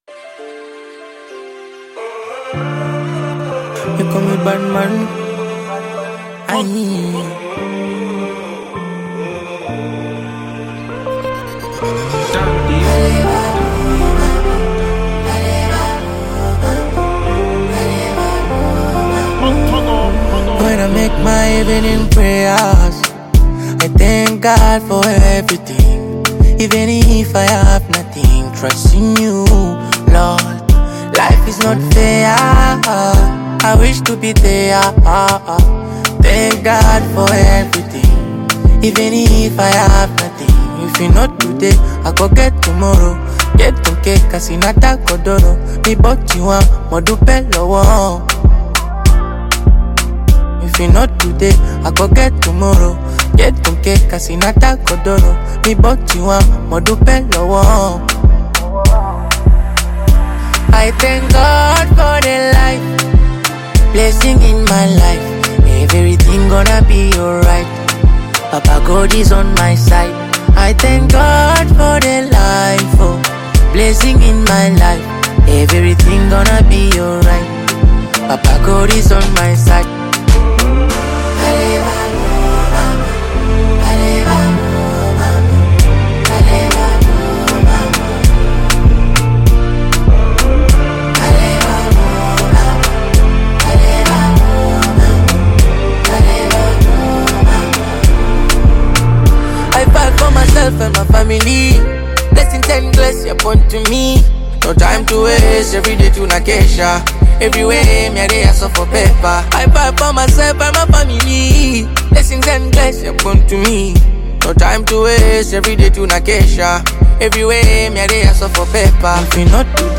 Download the Bongo Flava song